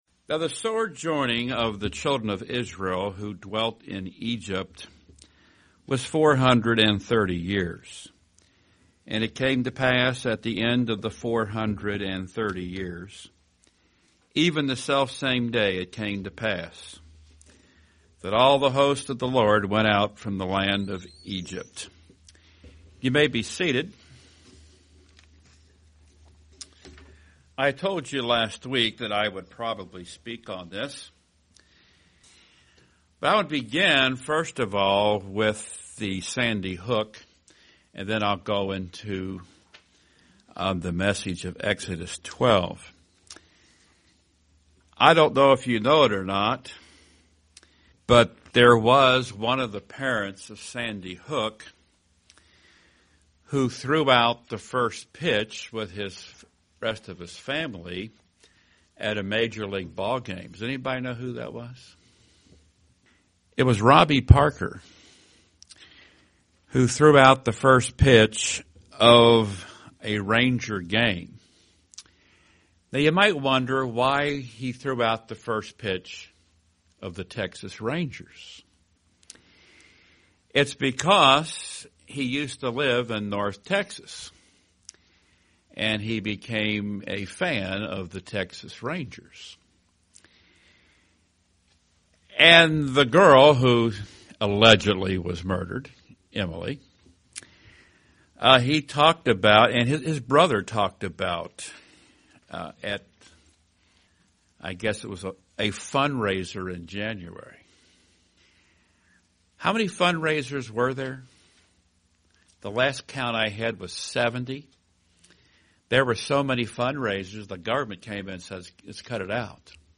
2013 Sermons